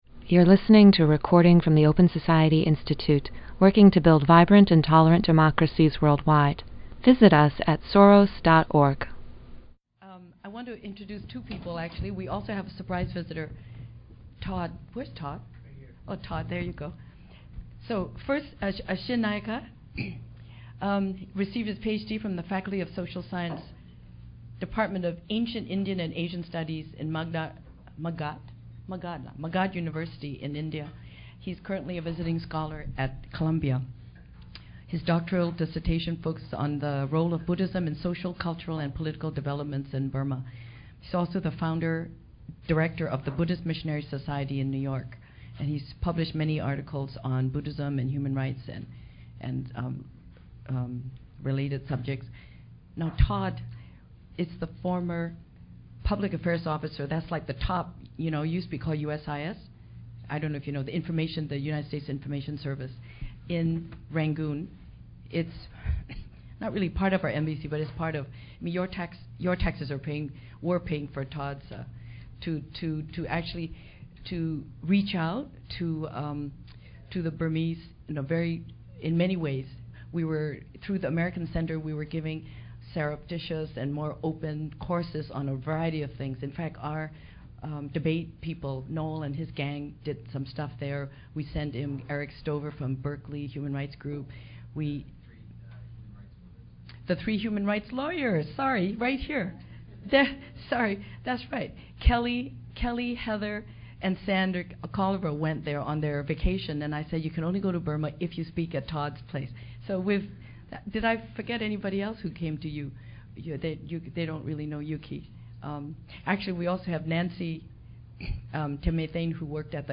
Update on the Burma Uprising (October 26, 2007) Download MP3 The Open Society Institute held an in-house update with Burma experts on current political conditions in the country. Topics discussed included how the uprising began, the challenges now faced by pro-democracy activists, and efforts to lobby the UN to enforce Security Council Resolution 1325 on women, peace, and security.